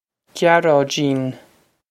Gyar-oh-jeen
This is an approximate phonetic pronunciation of the phrase.